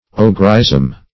Ogreism \O"gre*ism\